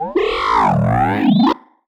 sci-fi_driod_robot_emote_neg_03.wav